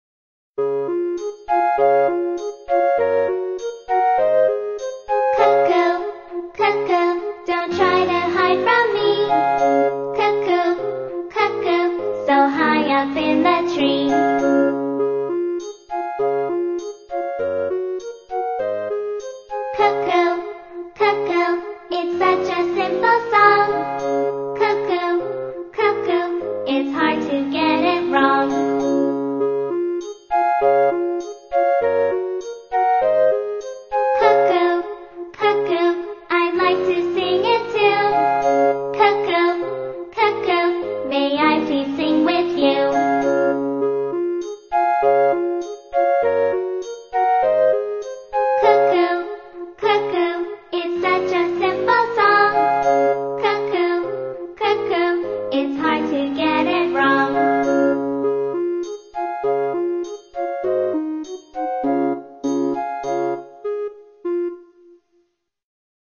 在线英语听力室英语儿歌274首 第27期:Cuckoo的听力文件下载,收录了274首发音地道纯正，音乐节奏活泼动人的英文儿歌，从小培养对英语的爱好，为以后萌娃学习更多的英语知识，打下坚实的基础。